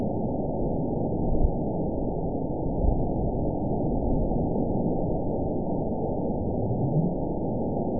event 922709 date 03/17/25 time 17:08:26 GMT (1 month, 2 weeks ago) score 9.37 location TSS-AB04 detected by nrw target species NRW annotations +NRW Spectrogram: Frequency (kHz) vs. Time (s) audio not available .wav